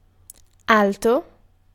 Ääntäminen
Vaihtoehtoiset kirjoitusmuodot (vanhentunut) deepe Synonyymit large thick bright rich great vivid low heavy voluminous profound meaningful low-pitched long fast deeply Ääntäminen : IPA : /diːp/ US : IPA : [diːp]